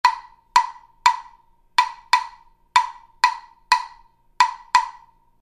LP1207 Jam Block Medium Pitch Red Videos and Sound Clips LP LP1207 Sound Sample 3 Customer Reviews Write a review Great sound Comments: This block adds a nice solid sound that contrasts the higher pitched yellow "Blast Block" that I use in my set up.